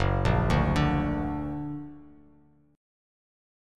F#+ Chord
Listen to F#+ strummed